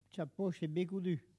Elle provient de Bouin.
Locution ( parler, expression, langue,... )